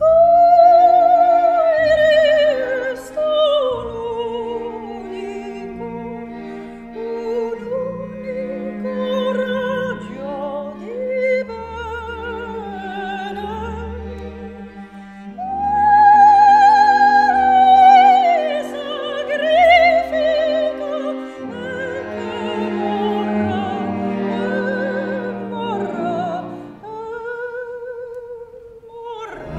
Un parfum entêtant et paradoxal de rêve et de réalité… !
au timbre de voix presque irréel